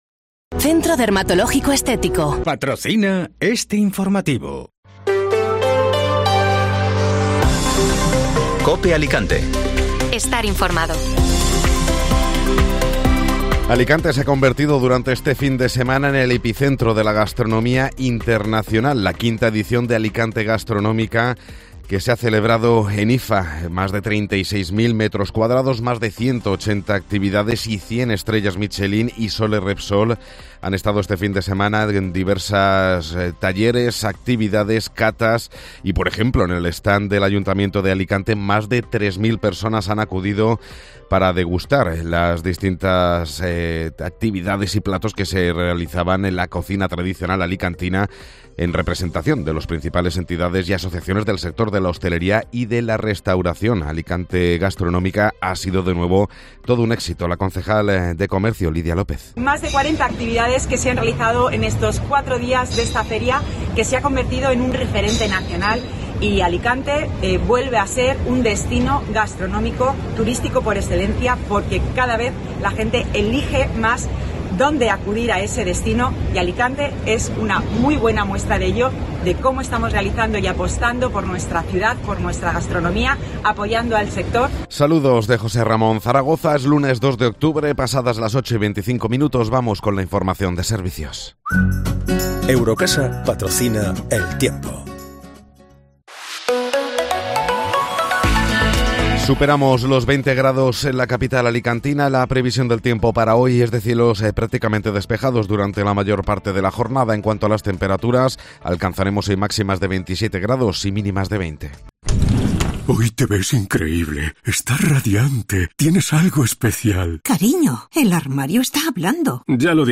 Informativo Matinal (Lunes 2 de Octubre)